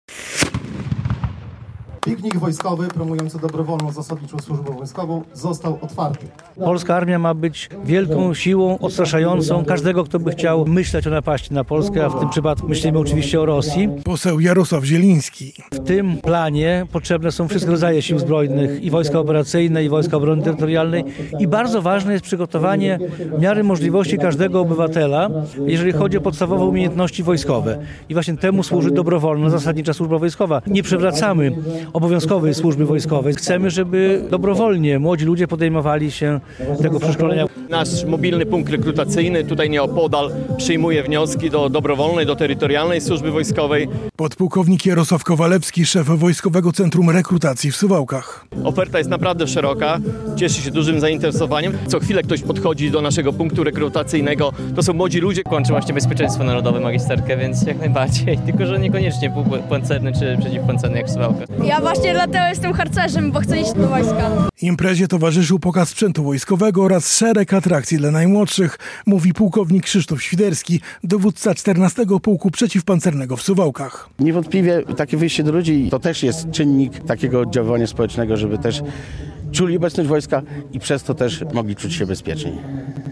Piknik wojskowy w Suwałkach